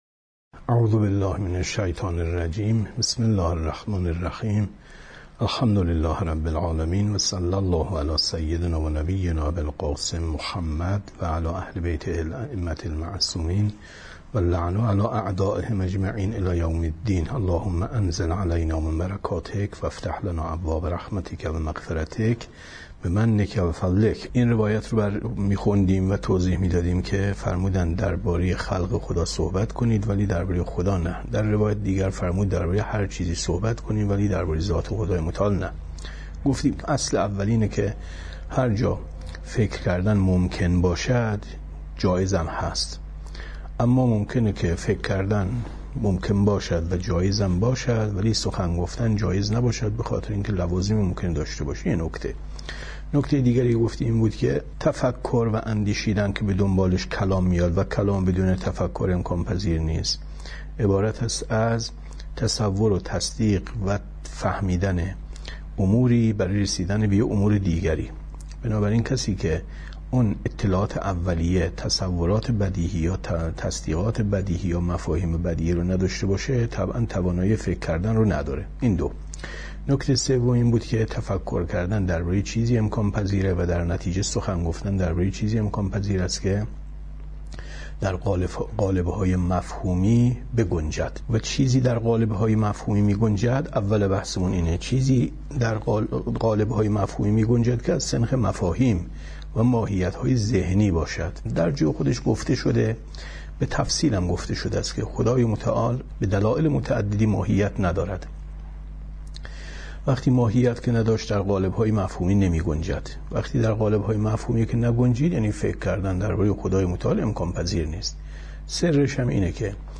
کتاب توحید ـ درس 44 ـ 21/ 10/ 95